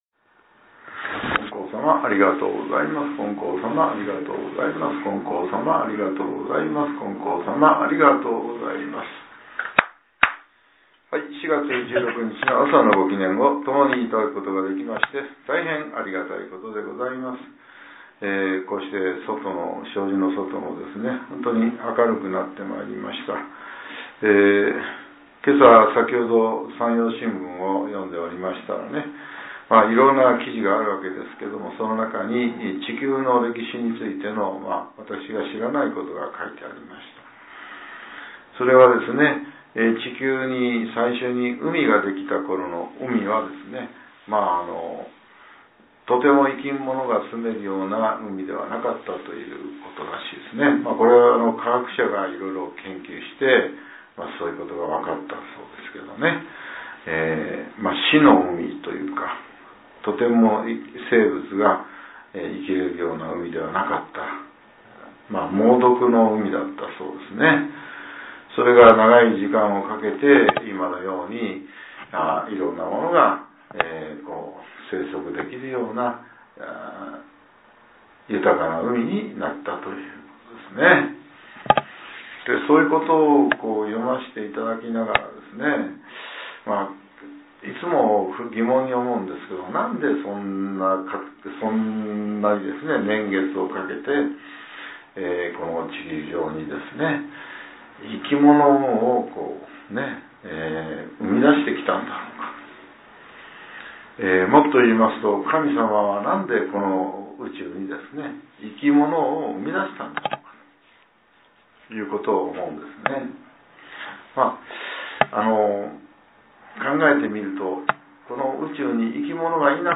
令和７年４月１６日（朝）のお話が、音声ブログとして更新されています。